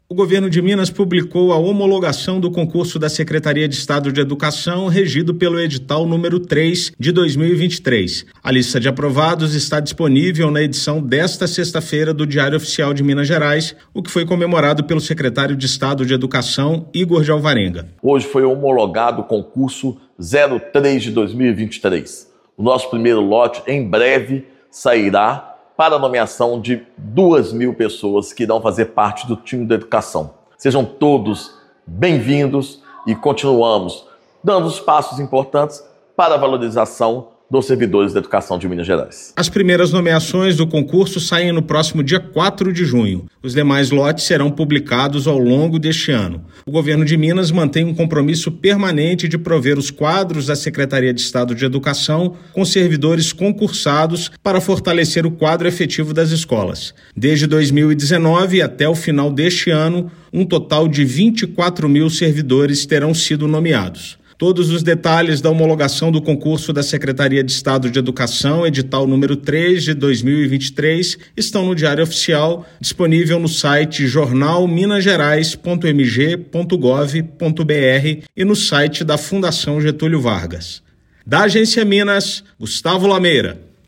Nestes cinco anos de gestão, o Estado convocou um número expressivo de candidatos para integrar o quadro das carreiras da educação estadual, com cerca de 24 mil nomeados. Ouça matéria de rádio.